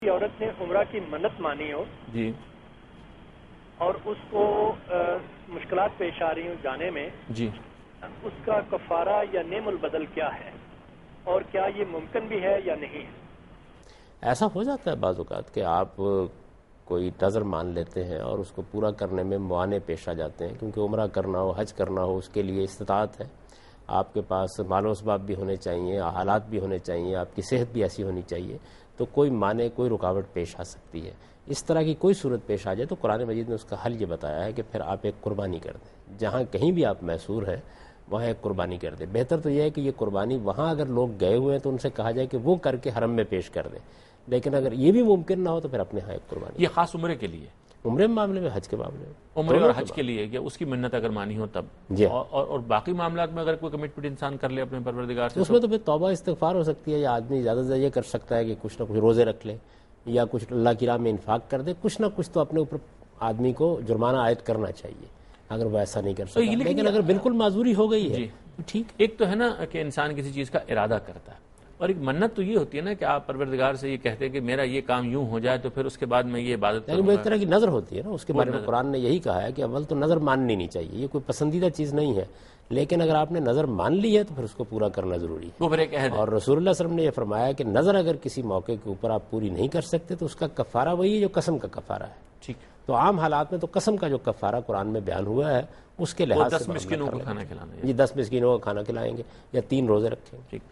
Category: TV Programs / Dunya News / Deen-o-Daanish / Questions_Answers /
دنیا نیوز کے پروگرام دین و دانش میں جاوید احمد غامدی ”منت پورا نہ کرنے کا کفارہ“ سے متعلق ایک سوال کا جواب دے رہے ہیں